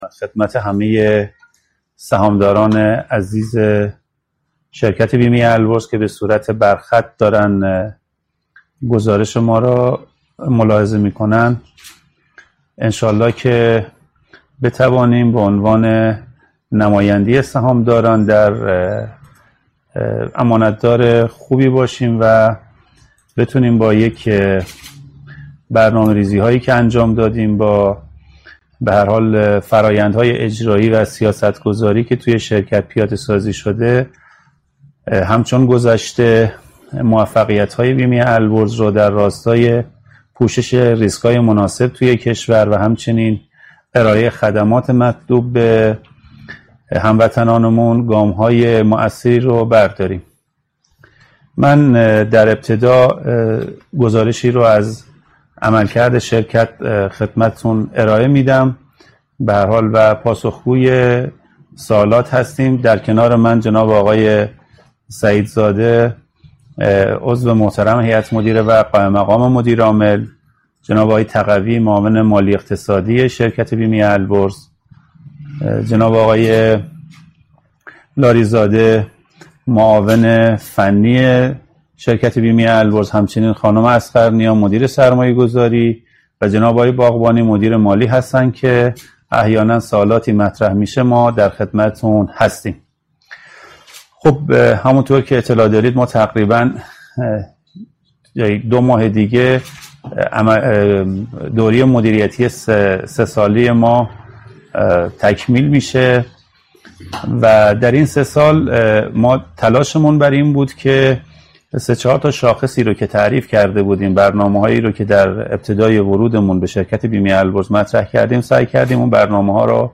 کنفرانس آنلاین پرسش و پاسخ سهامداران و مدیران شرکت بیمه البرز- نماد:البرز